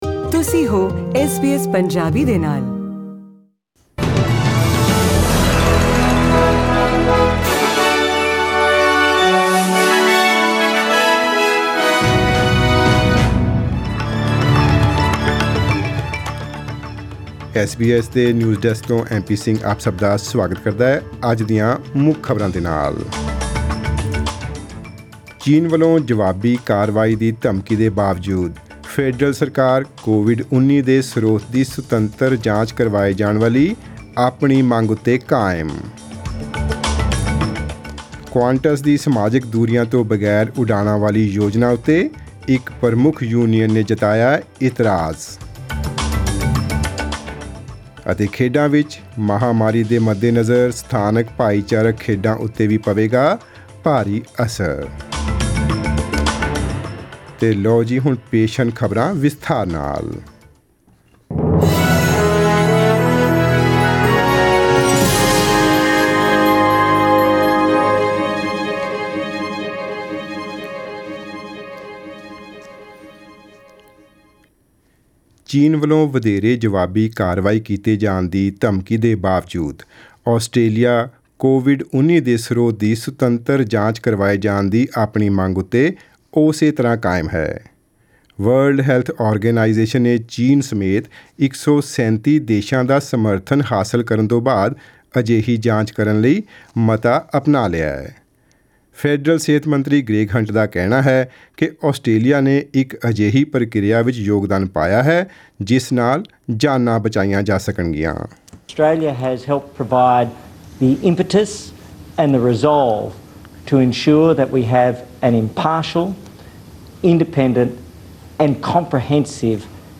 In today’s news bulletin: The federal government stands by its push for the independent inquiry into the origins of COVID-19, despite the threat of more retaliation from China; A major union savages Qantas' plans to return to normal flying without social distancing onboard aircraft; and concern for local amateur sport in the wake of the pandemic